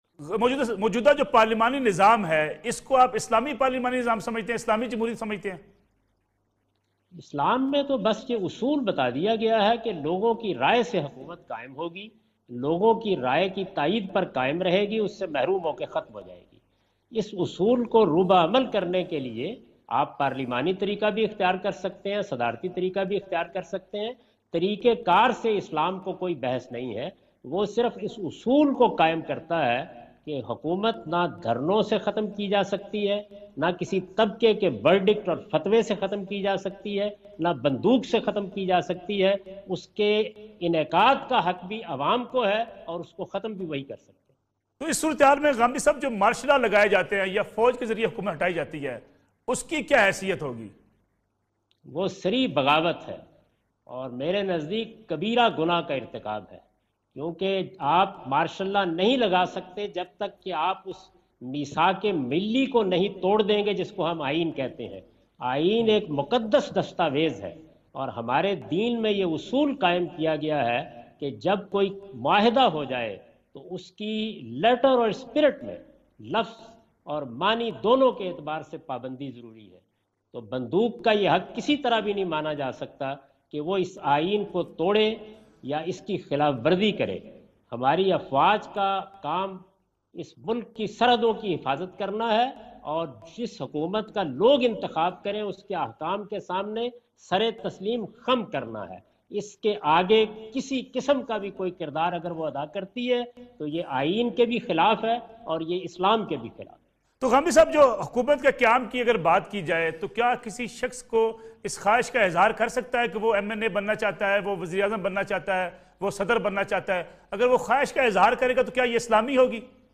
Category: TV Programs / Neo News /
In this program Javed Ahmad Ghamidi answer the question about "Is Parliamentary form of Government Islami" on Neo News.